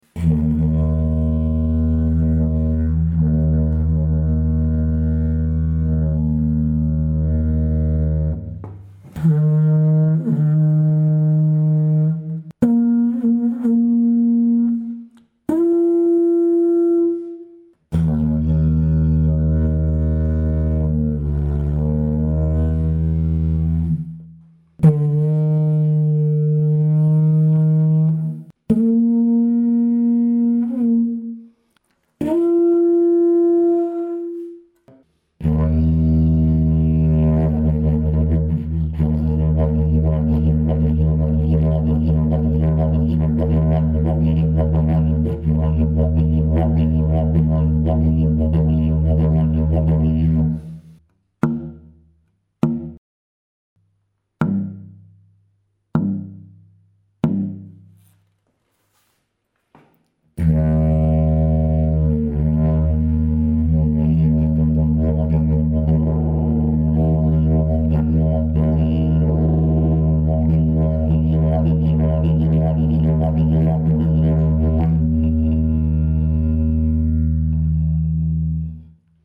ist ein mittelgroßes Didge mit Stimmlage D#2, violett blau mit leicht keramisch metallischem Glanz, innen ocker, siena, 2,7kg, es spricht sehr leicht an, braucht wenig Luft, hat kräftigen Grundton, hat ein etwas schmäleres Munststück 29mm innen mit 4,5 mm Wandstärke.
D2# (-20, +30) // D3#-10 / Bb3+-5 / F4-40
is a medium-sized didgeridoo with a D#2 pitch, violet-blue with a slightly ceramic-metallic sheen, ochre-siena interior, 2.7 kg. It responds very easily, requires little air, has a strong fundamental tone, and features a slightly narrower mouthpiece (29 mm inner diameter) with a 4.5 mm wall thickness.
To summarize, it is a compact, complex cylindrical didgeridoo with a strong resonance in D#2 ( D-sharp)